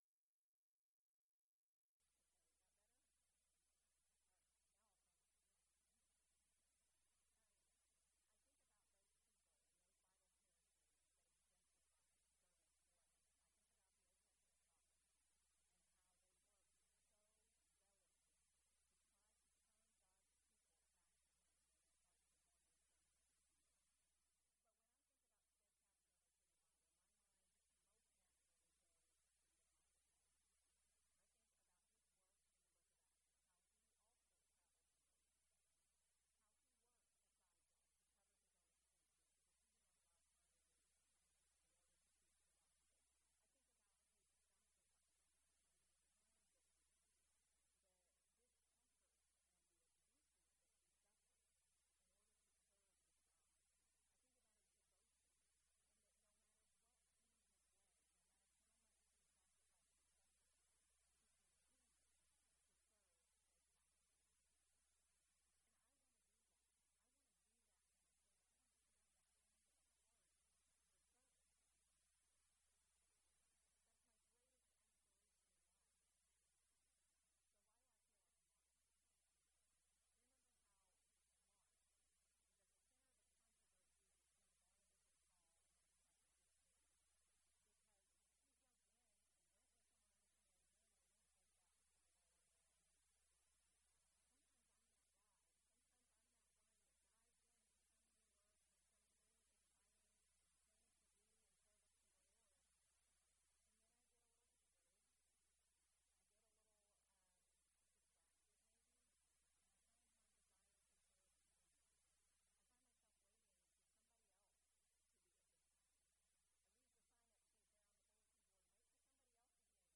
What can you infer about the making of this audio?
Event: 2017 Focal Point